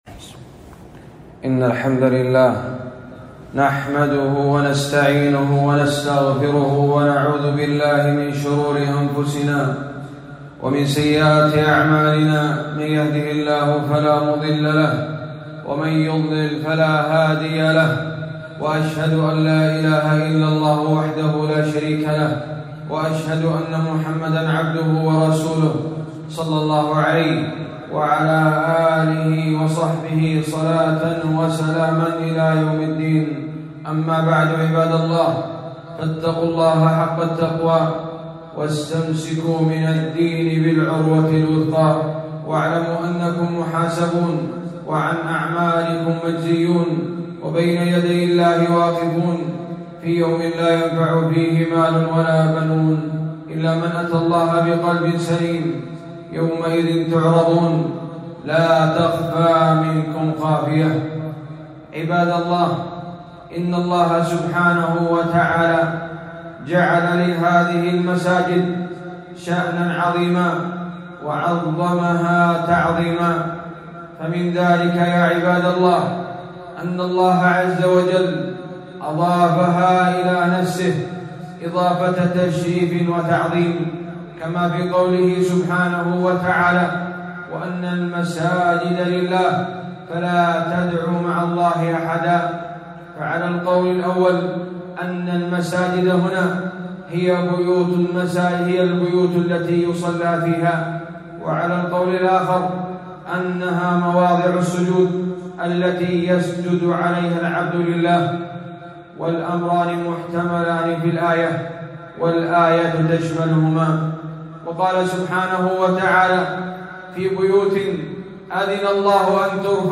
خطبة - مكانة المساجد والعناية بها